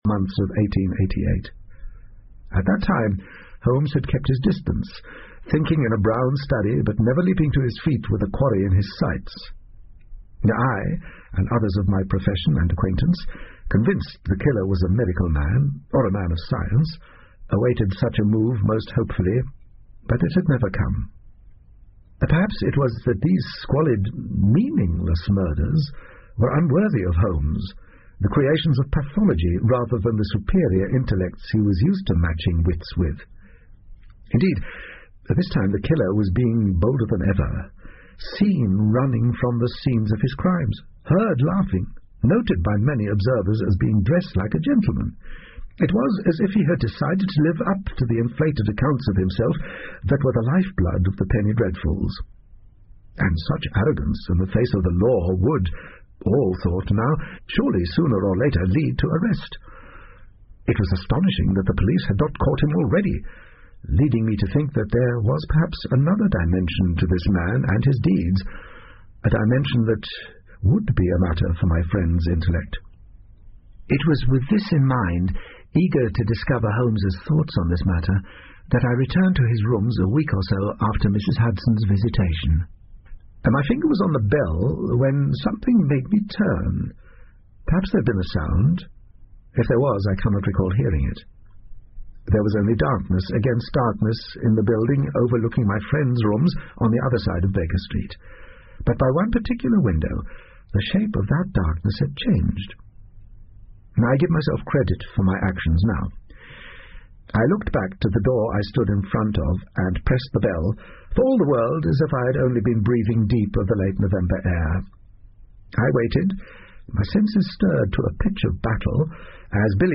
福尔摩斯广播剧 Cult-The Deer Stalker 2 听力文件下载—在线英语听力室